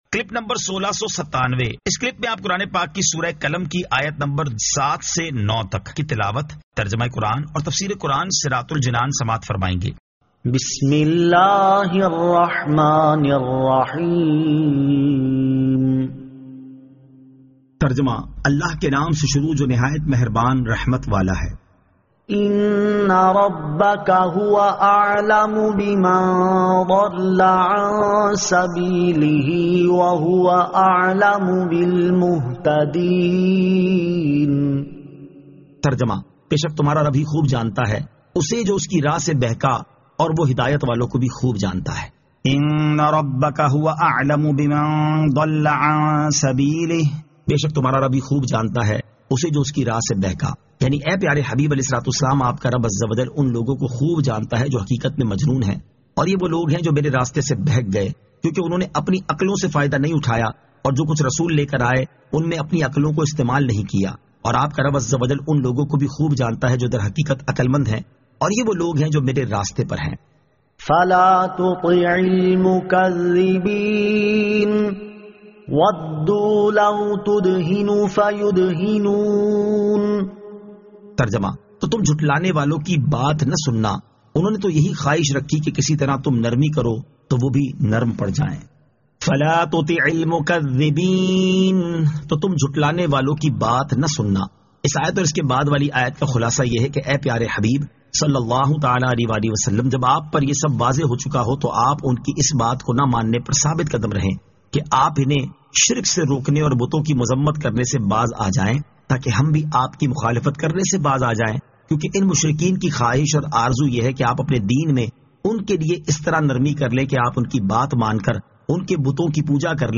Surah Al-Qalam 07 To 09 Tilawat , Tarjama , Tafseer